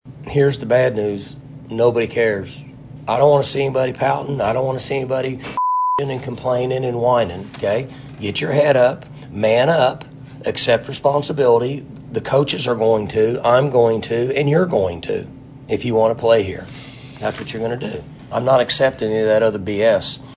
Postgame head coach Mike Gundy says no one will care to hear any excuses out of him or his team.
Gundy Postgame 11-11.mp3